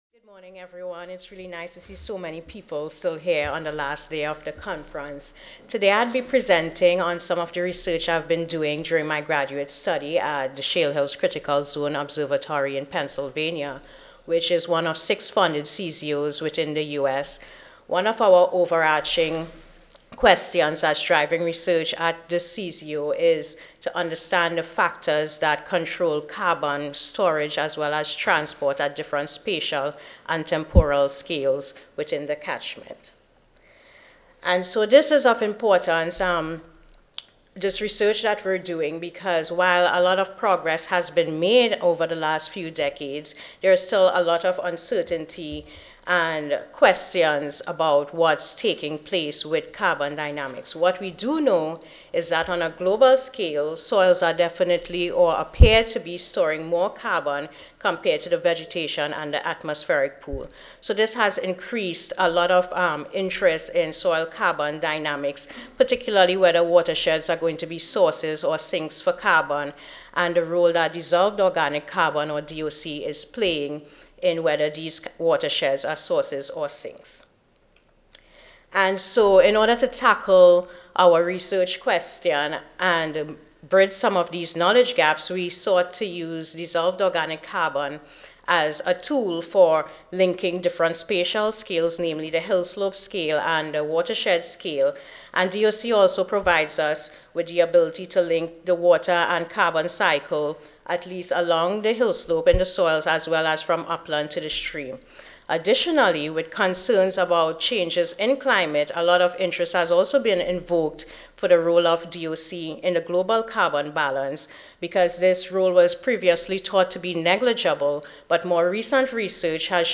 S07 Forest, Range & Wildland Soils Session: Soil Carbon Dynamics (2010 Annual Meeting (Oct. 31 - Nov. 3, 2010))
Pennsylvania State University Audio File Recorded presentation